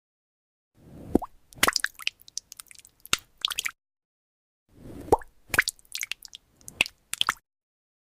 Bubble wrap popping… This sound sound effects free download
This sound Mp3 Sound Effect Bubble wrap popping… This sound is too satisfying!!